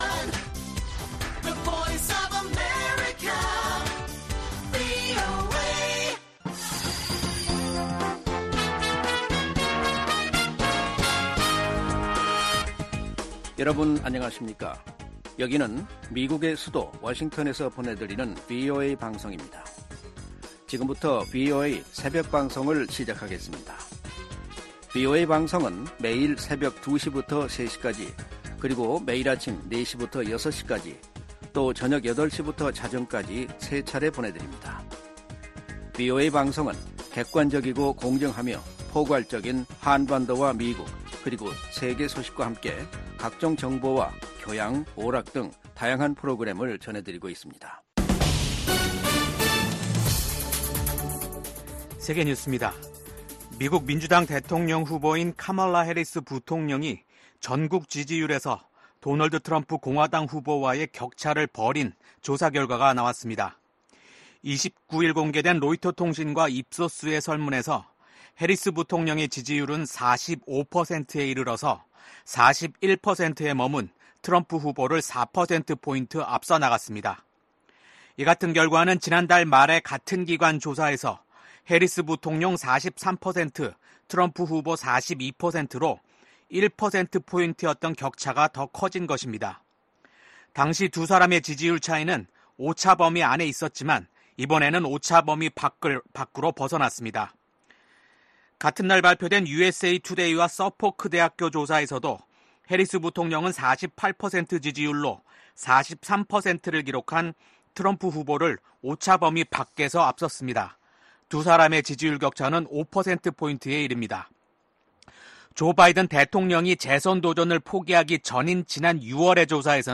VOA 한국어 '출발 뉴스 쇼', 2024년 8월 31일 방송입니다. 북한 해군 자산들이 국제해사기구(IMO) 자료에서 사라지고 있습니다.